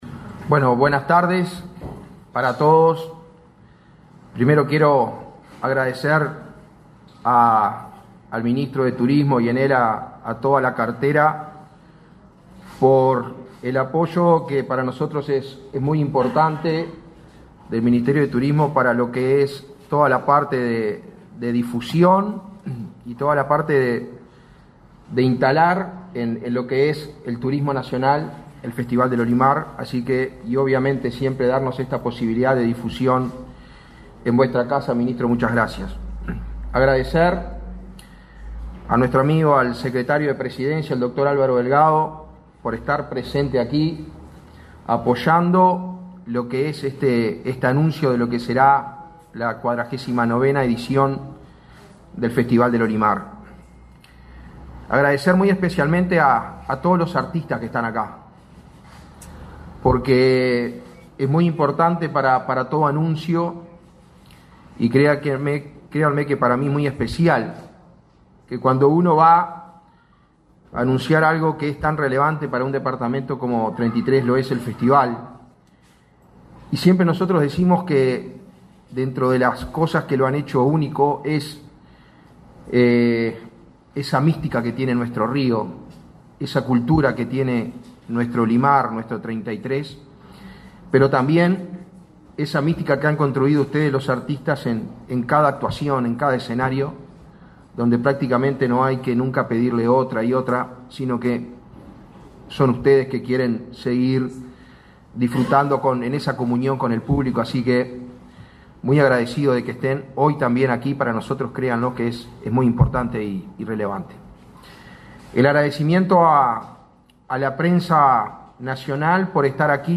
Conferencia de prensa por el lanzamiento de la 49.ª edición del Festival del Olimar
Participó el secretario de la Presidencia, Álvaro Delgado; el ministro de Turismo, Tabaré Viera, y el intendente departamental, Mario Silvera.